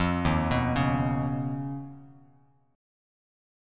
Eb7sus2#5 chord